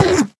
1.21.5 / assets / minecraft / sounds / mob / endermen / hit2.ogg
hit2.ogg